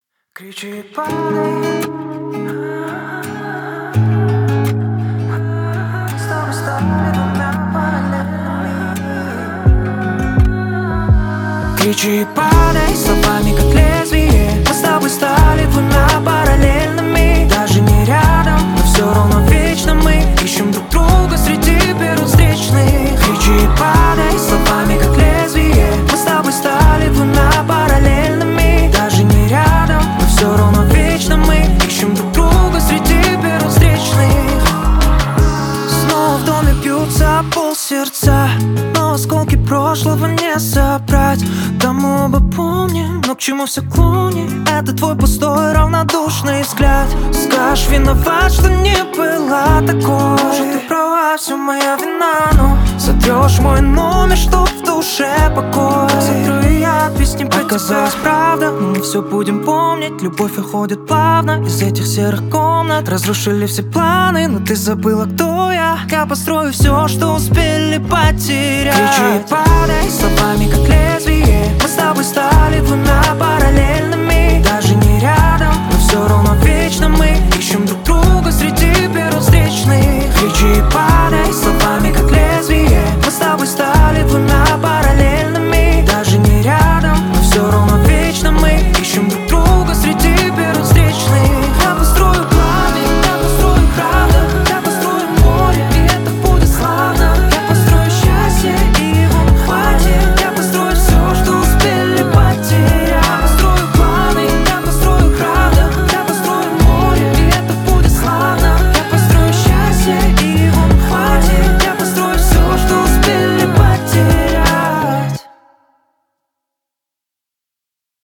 Драм-н-басс